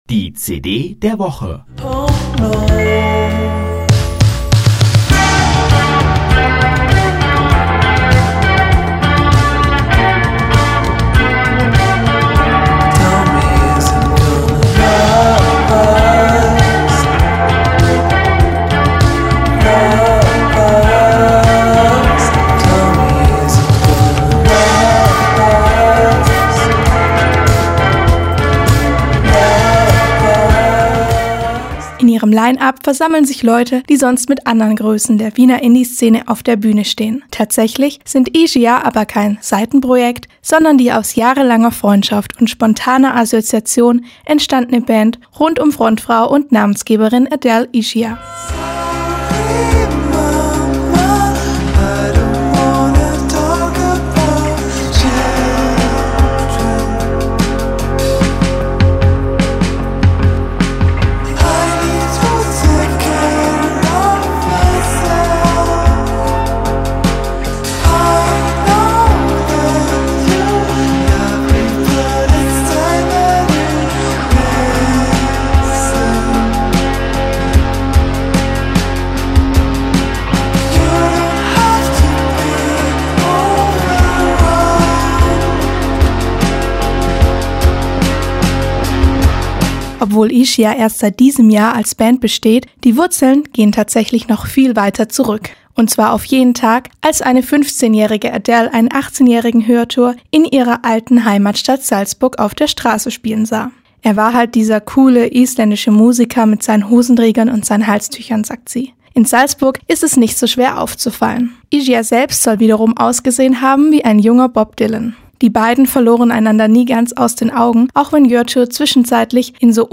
sondern auch die neuste Indie-Pop-Band aus Wien.